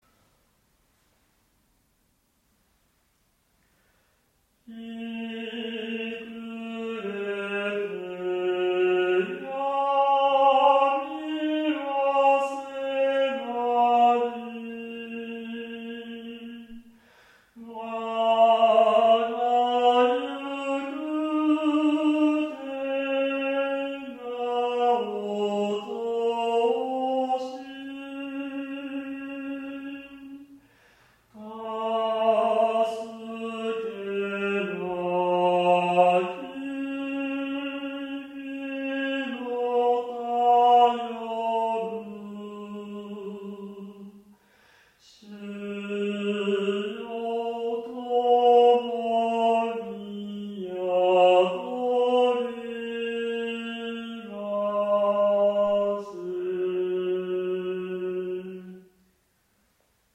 １９世紀的なロマンティックな抒情性とが巧く折り重なった讃美歌です。
個人的に歌いやすいようにピッチを上げて歌ってみました。
ミーントーンは平均律に比べ少し垂れ下がった感覚があります。